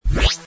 hud_expand.wav